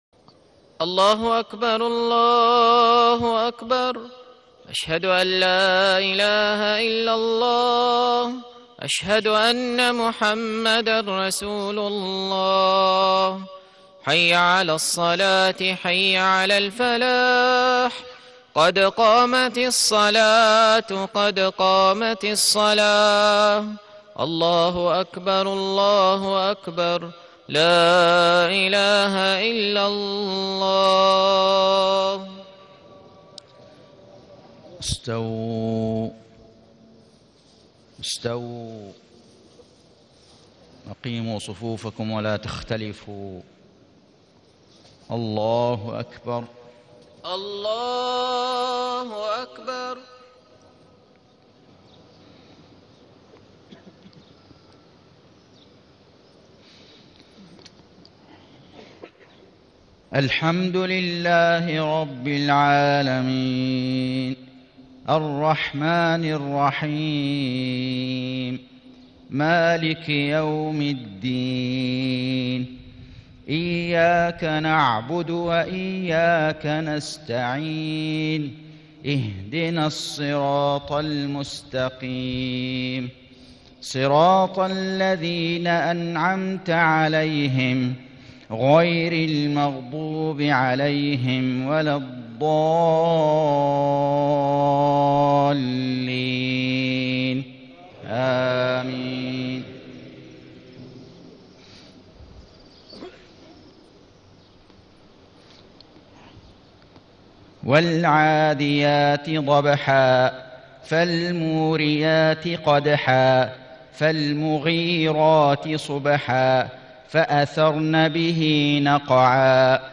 صلاة المغرب 1-5-1437هـ سورتي العاديات و القارعة > 1437 🕋 > الفروض - تلاوات الحرمين